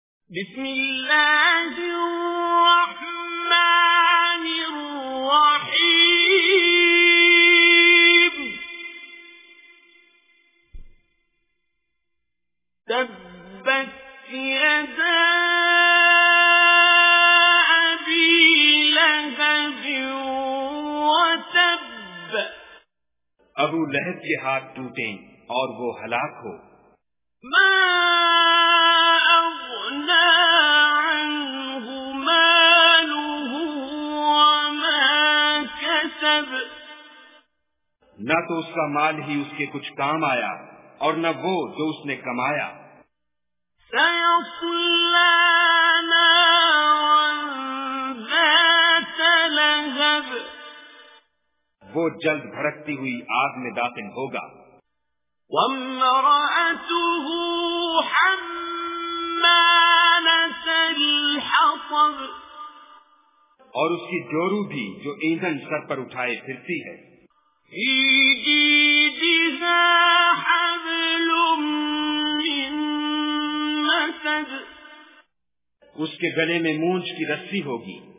Surah Lahab Recitation with Urdu Translation
Surah Lahab is 111 Surah of Holy Quran. Listen online mp3 tilawat / recitation of Surah Lahab in the voice of Qari Abdul Basit As Samad.